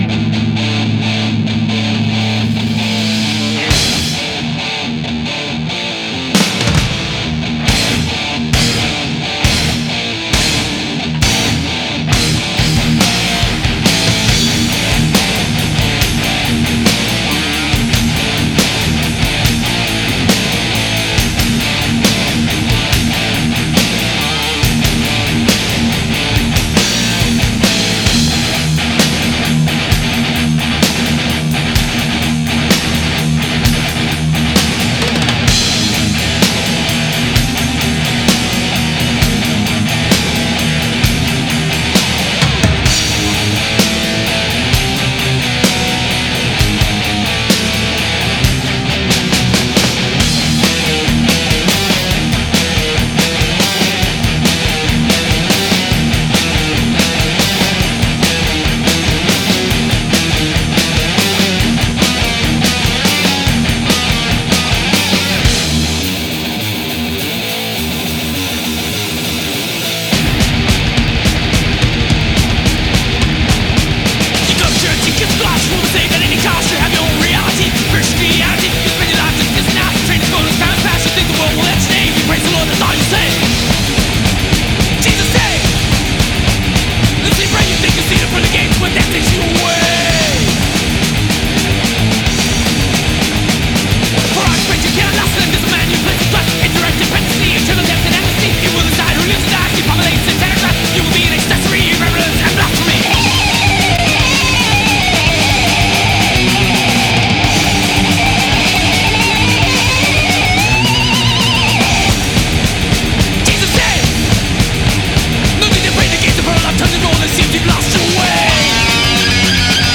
Жанр: Thrash, Speed Metal